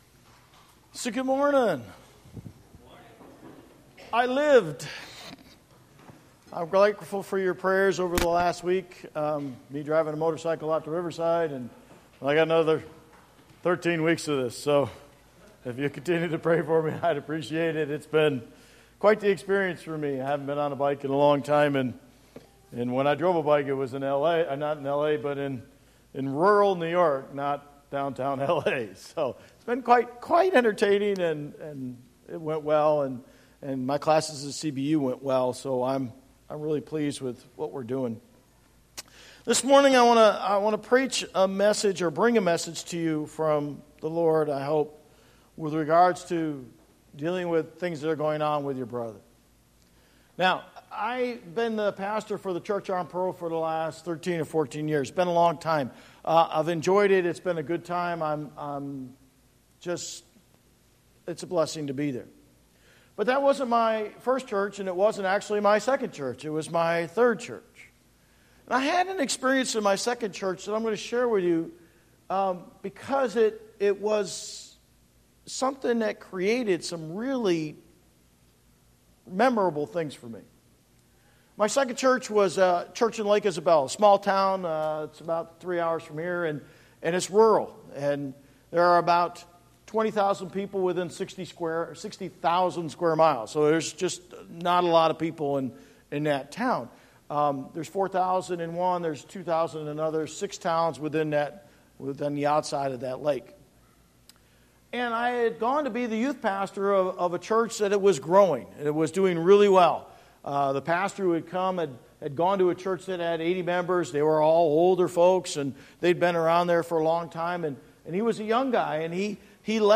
Bible Text: Matthew 18:15-17 | Preacher